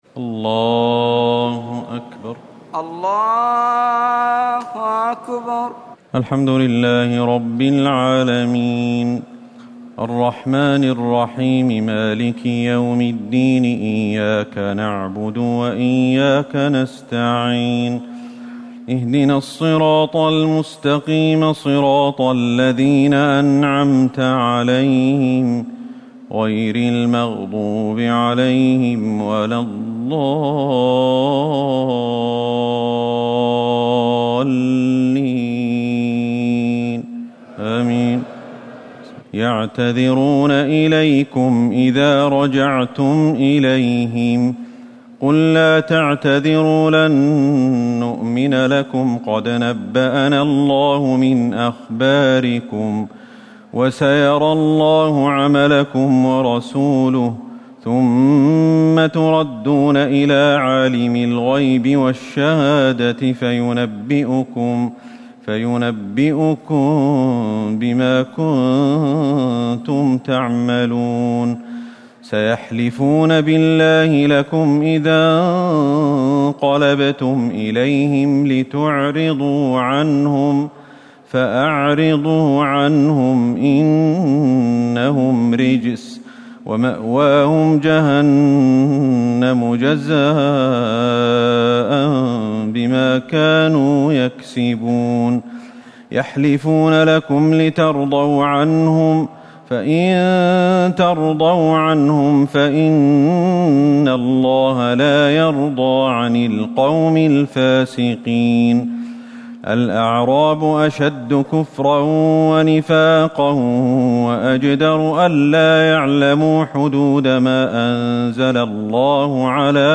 تراويح الليلة العاشرة رمضان 1439هـ من سورتي التوبة (94-129) و يونس (1-25) Taraweeh 10 st night Ramadan 1439H from Surah At-Tawba and Yunus > تراويح الحرم النبوي عام 1439 🕌 > التراويح - تلاوات الحرمين